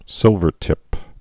(sĭlvər-tĭp)